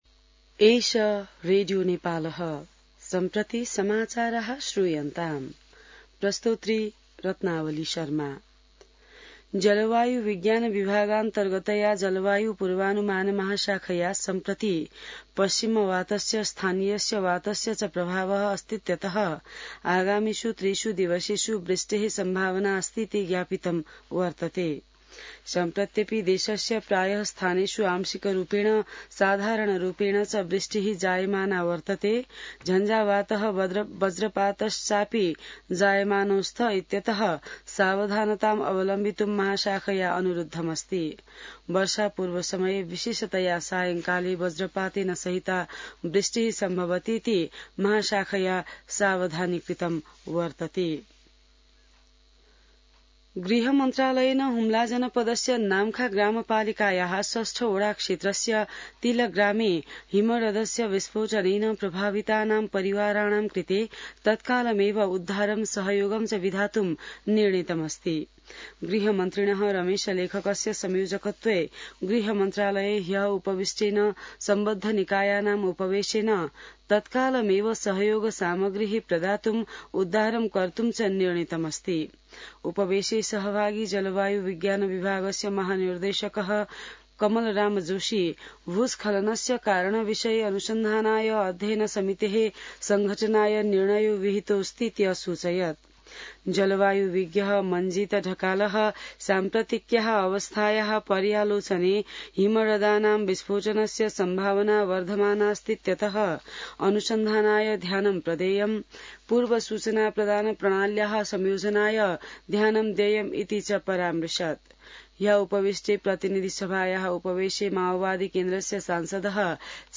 संस्कृत समाचार : ६ जेठ , २०८२